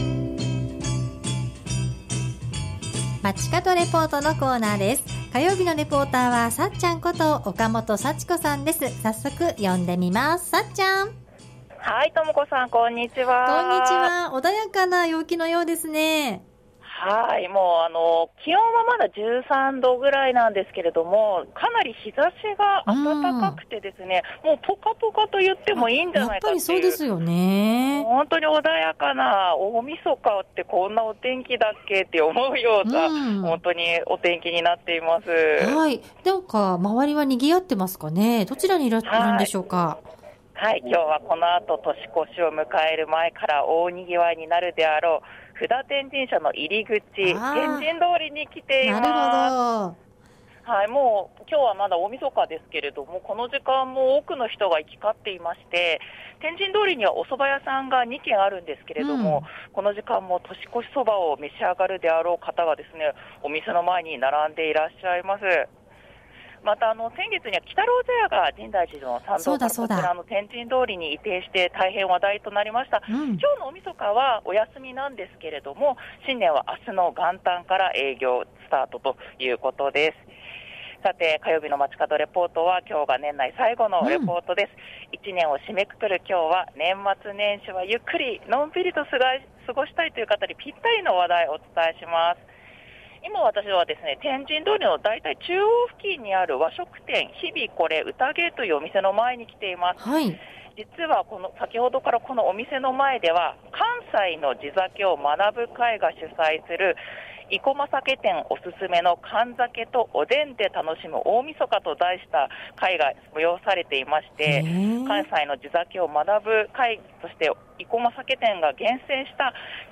街角レポート、一年を締めくくる中継は このあと、年越し迎える前から大賑わいになるであろう布田天神社の入り口＝天神通りからお伝えしました。